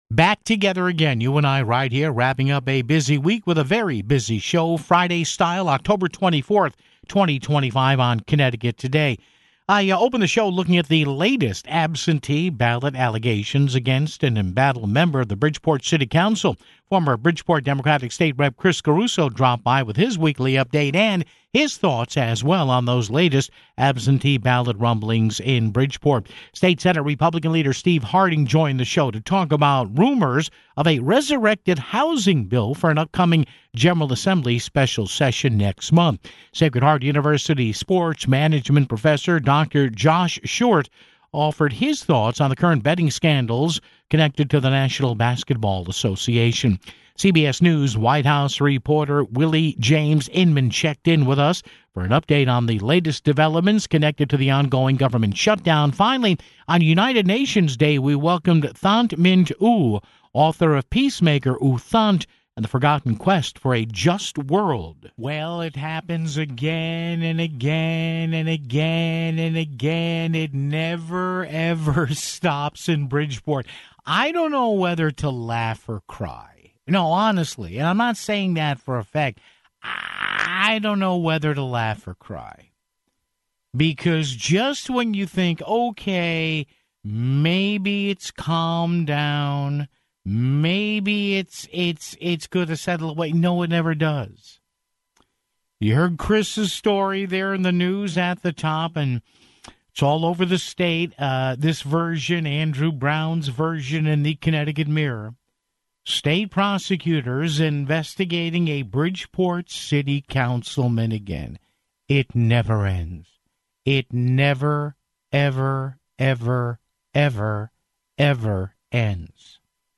Former Bridgeport Democratic State Rep. Chris Caruso dropped by with his weekly update and thoughts on those latest absentee ballot rumblings (16:06). State Senate GOP Leader Steve Harding joined the show to talk about rumors of a resurrected housing bill for an upcoming General Assembly special session next month (25:23).